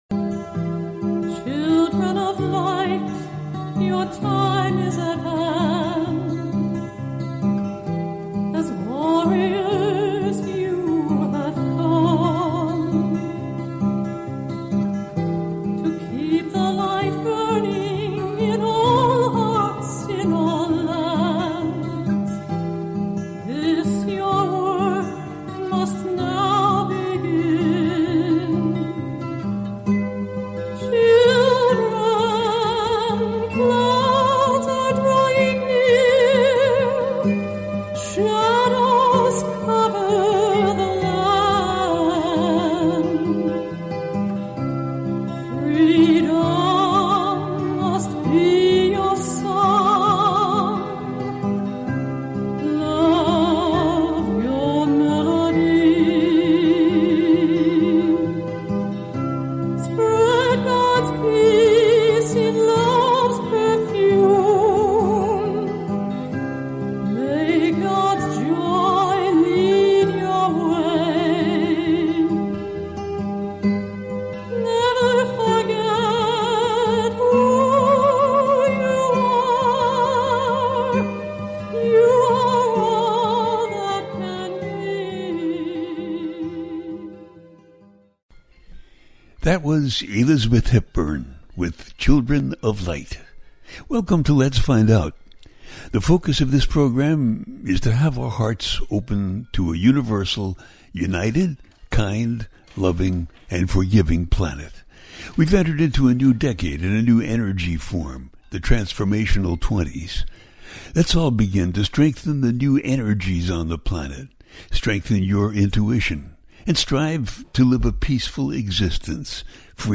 The New Sagittarius Moon and finishing up 2024 - A teaching show